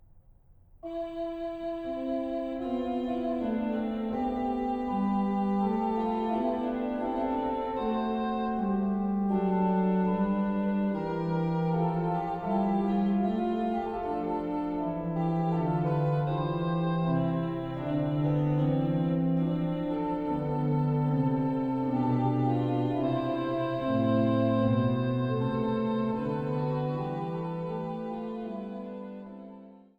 Weihnachtliche Orgelmusik